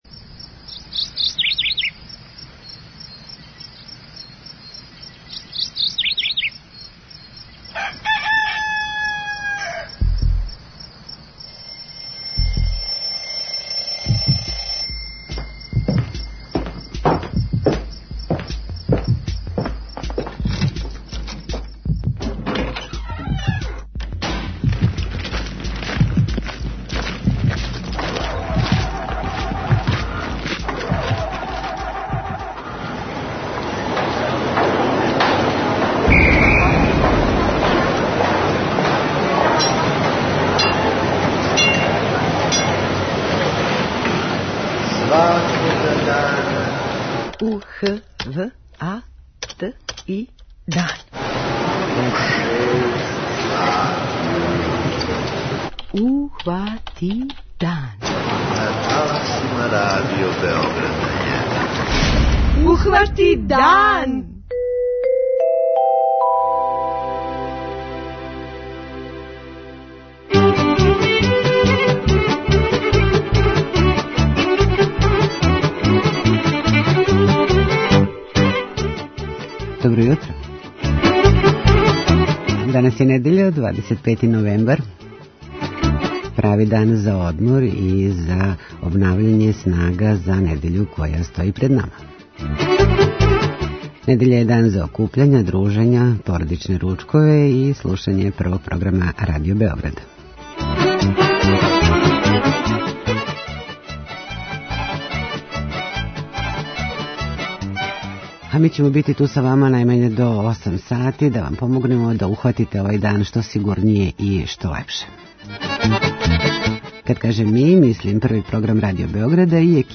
Међу њима су постројења у Шапцу и Крагујевцу, о којима ћемо више чути од наших дописника.